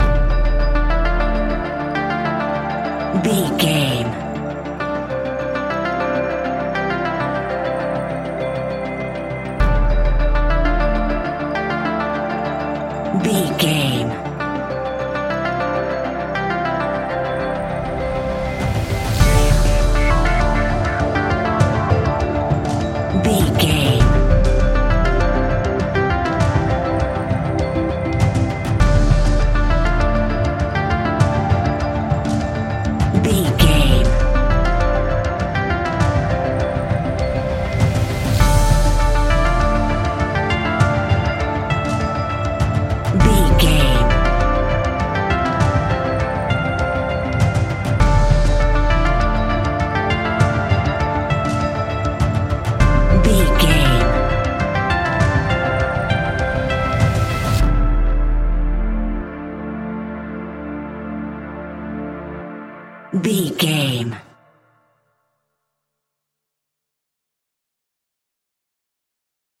Aeolian/Minor
D
scary
tension
ominous
dark
dramatic
haunting
eerie
synthesiser
drums
percussion
horror music
Horror Pads